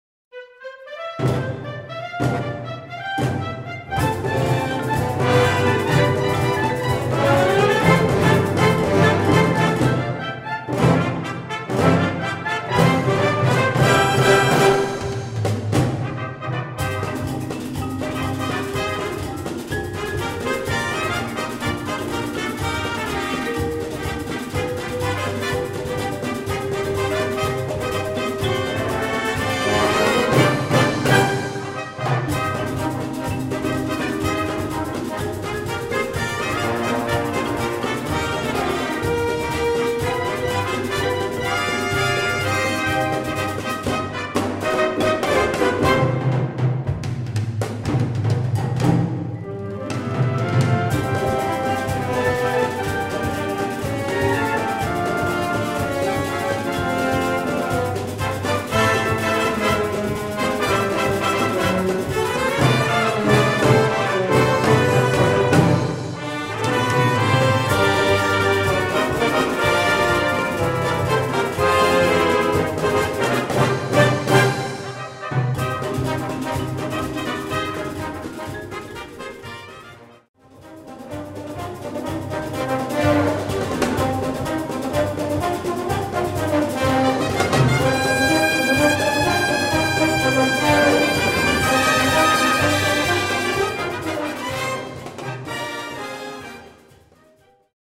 Gattung: Konzertante Blasmusik
Besetzung: Blasorchester